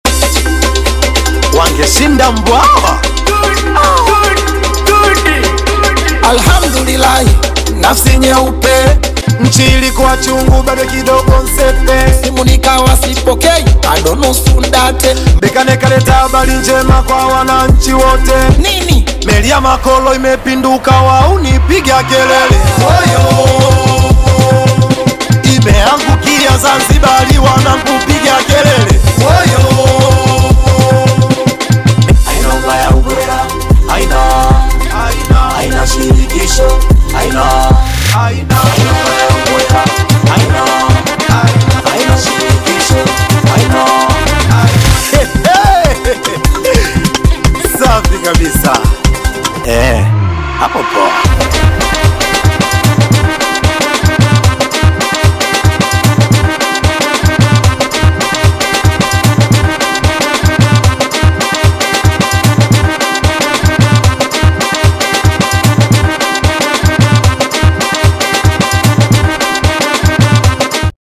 high-energy Singeli single
Genre: Singeli